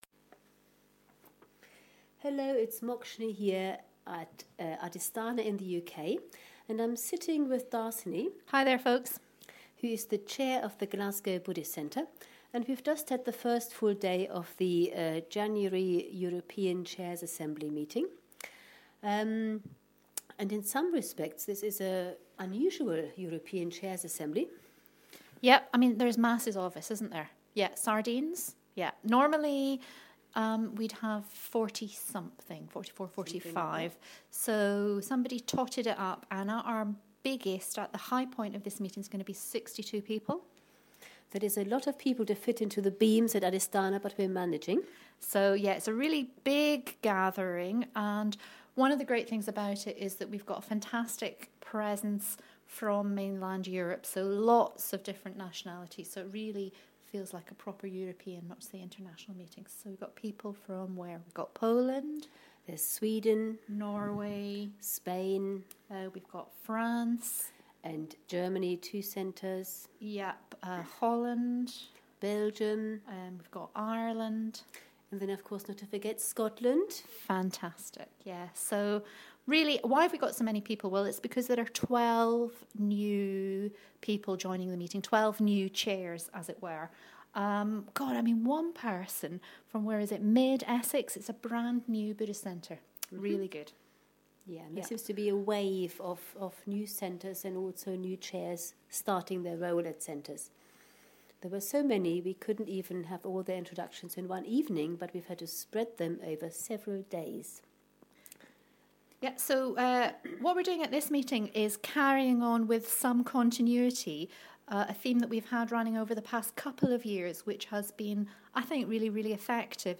It's January and time for the winter European Chairs Assembly meeting, held at Adhistana, UK.